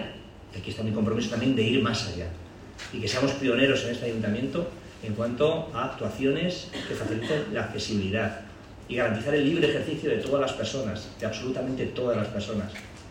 Así lo ha anunciado durante la clausura de la jornada “Encuentros con Talento”, en Toledo, organizado por Inserta Empleo, entidad de la Fundación ONCE para la formación y el empleo de personas con discapacidad.
Cortes de voz